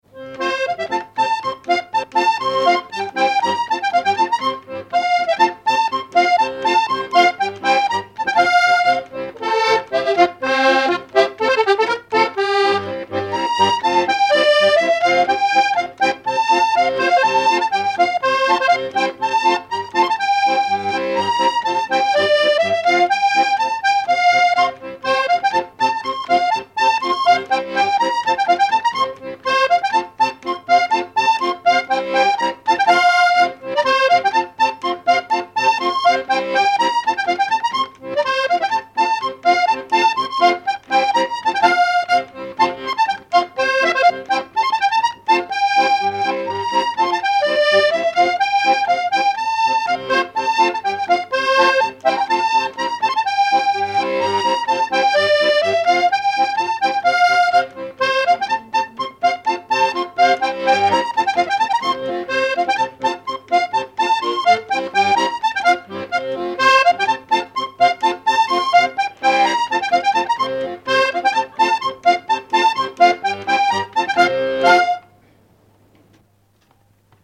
accordéon(s), accordéoniste ; musique traditionnelle ;
danse : quadrille ;
Répertoire des danses à l'accordéon diatonique
Pièce musicale inédite